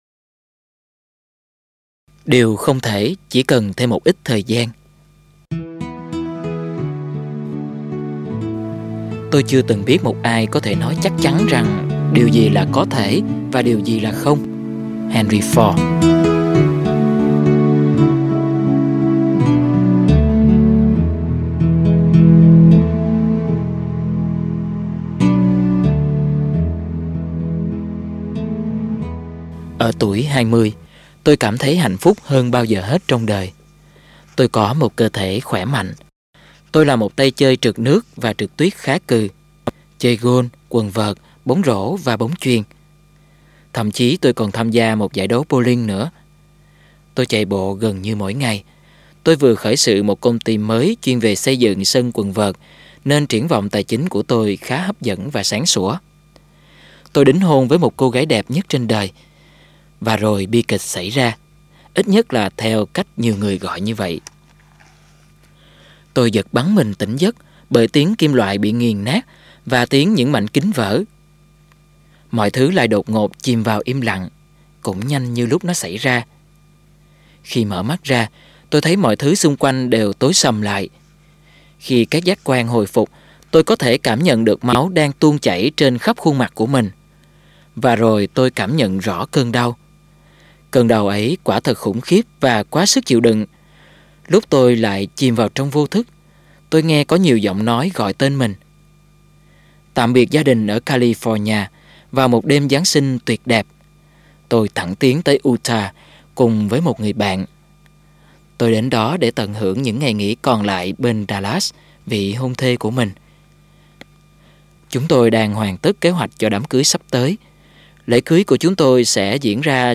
Sách nói Chicken Soup 13 - Sống Với Ước Mơ - Jack Canfield - Sách Nói Online Hay